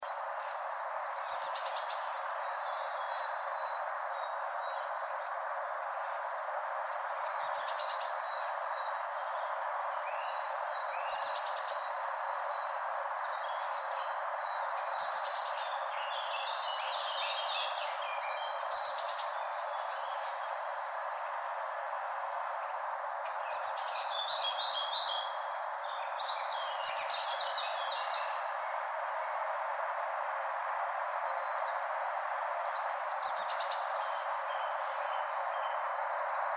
Vögel
Blaumeise
blaumeise.mp3